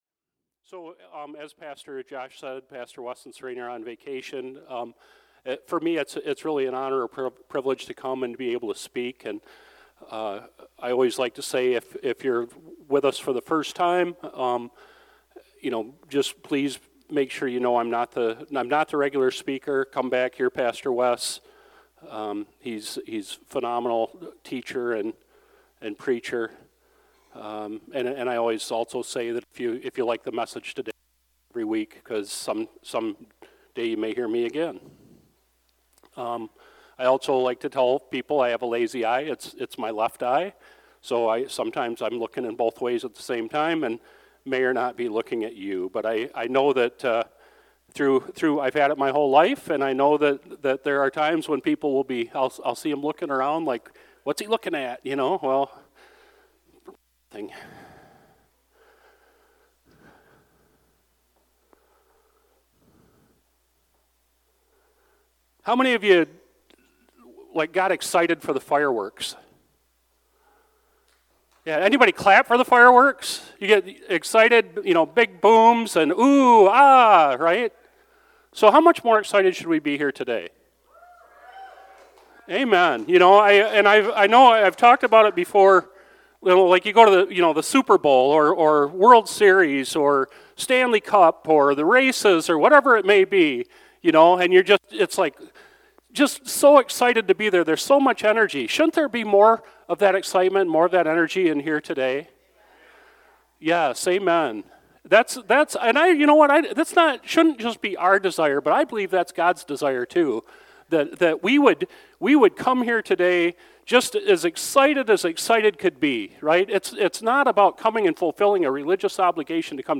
Sermon-7-06-25.mp3